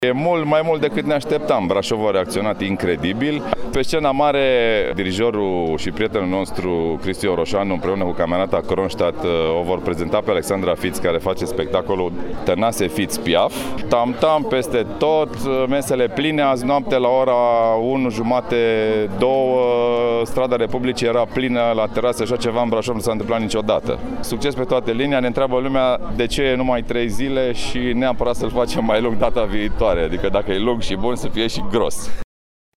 Iată ce a declarat și unul dintre organizatorii festivalului Tam-Tam după trei zile de evenimente în centrul orasului:
organizator-tam-tam.mp3